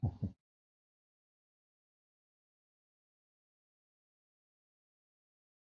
你的心脏跳动
描述：用了过滤器，lo fi，扭曲和其他我不记得的东西。
标签： 心脏 心脏跳动 你的心脏跳动
声道单声道